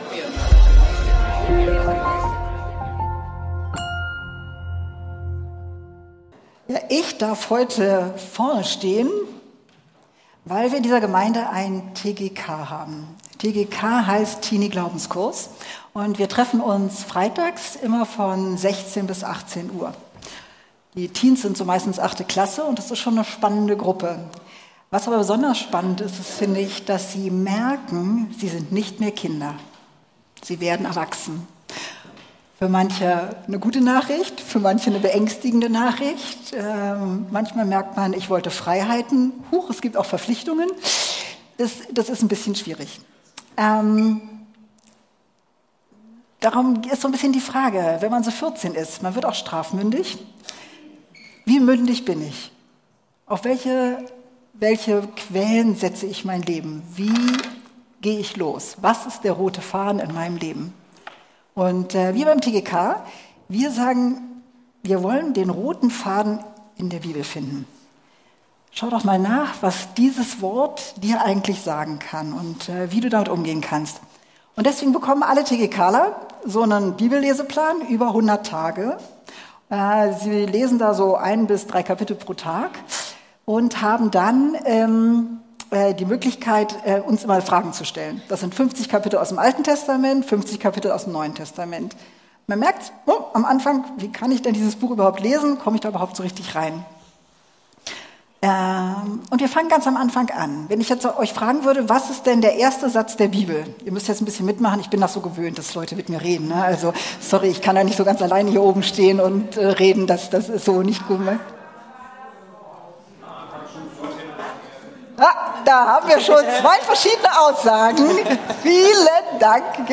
Der rote Faden - Predigten der LUKAS GEMEINDE - Podcast